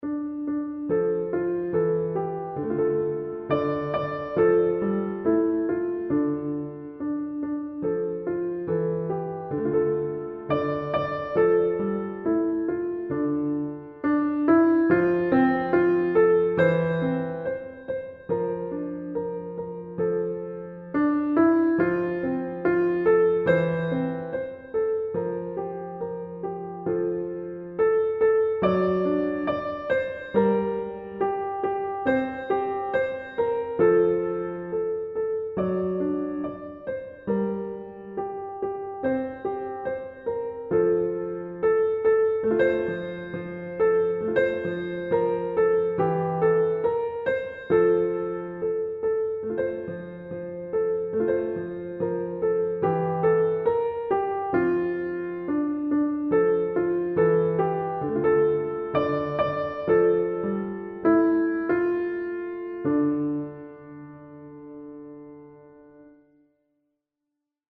réélaboré dans le temps de metronome et dans la dynamique..
carousel_piano_341.mp3